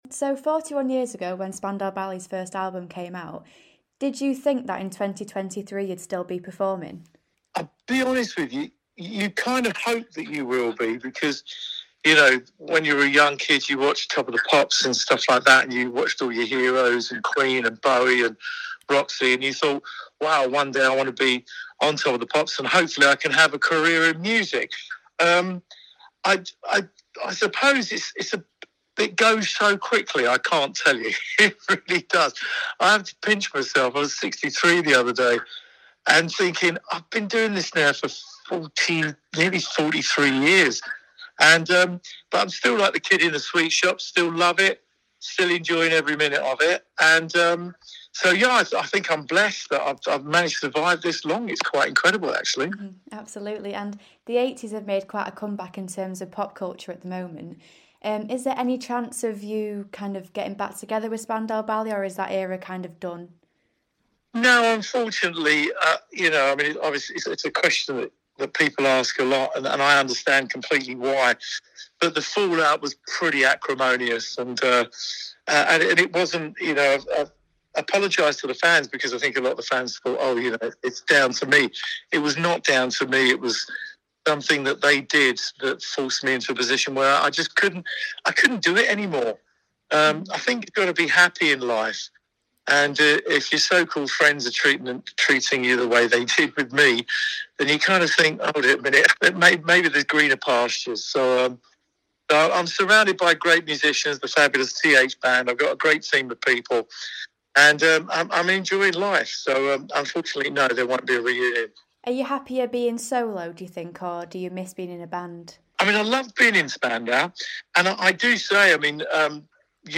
INTERVIEW: Tony Hadley on his upcoming tour and nostalgia of the 80s